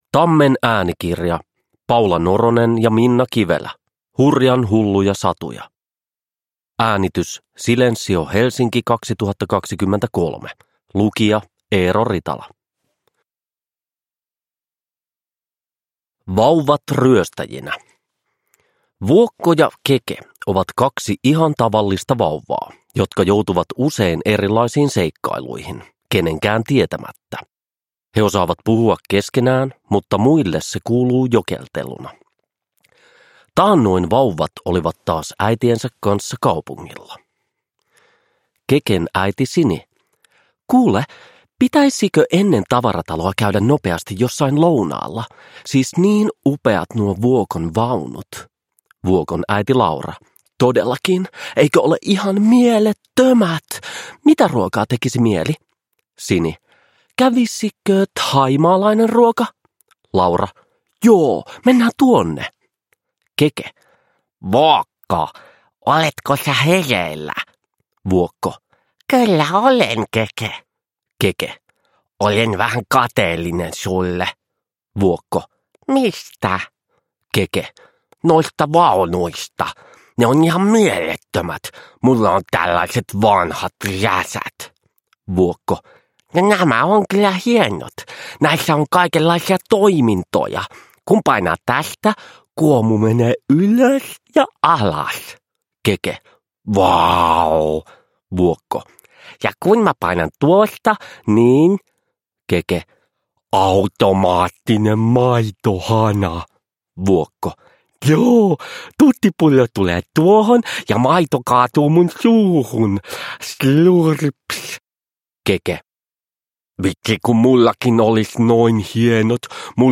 Hurjan hulluja satuja – Ljudbok – Laddas ner
Lukijana rakastettu ja palkittu Eero Ritala.
Uppläsare: Eero Ritala